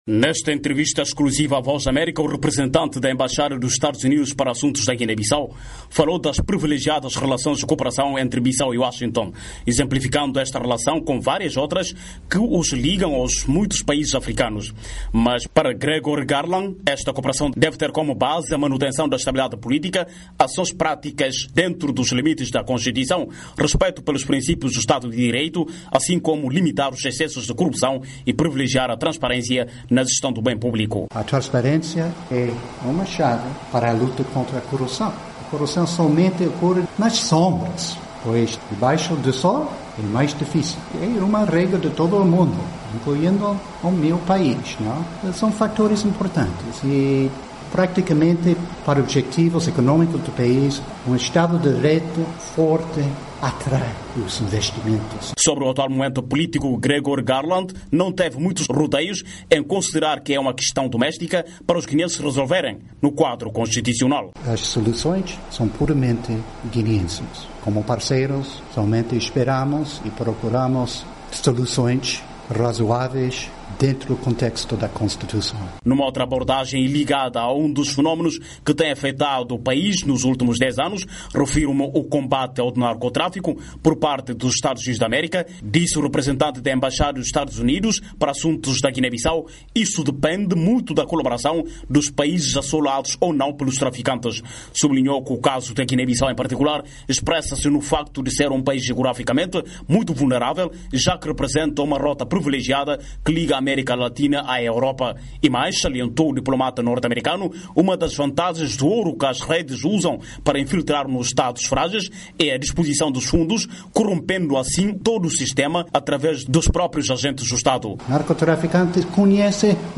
Gregory Garland fala à VOA.
Diplomata americano fala à VOA sobre relações Washington-Bissau